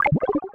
Magic4.wav